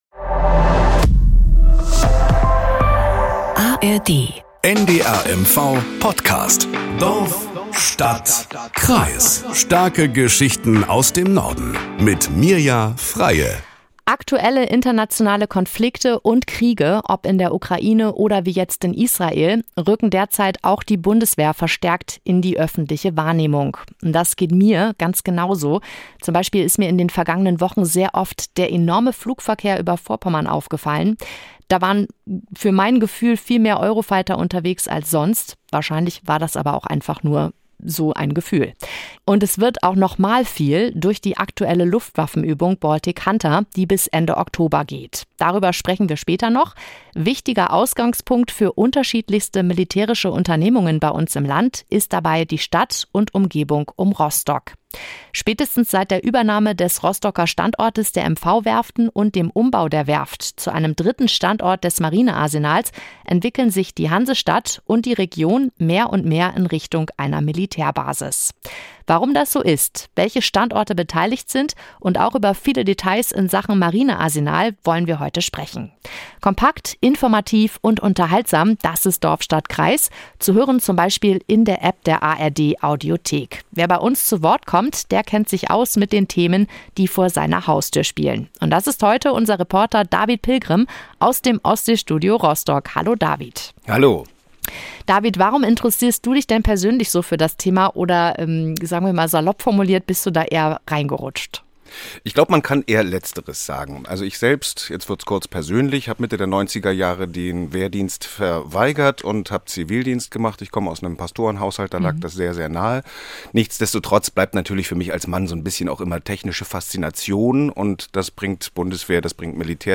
Nachrichten aus Mecklenburg-Vorpommern - 20.01.2024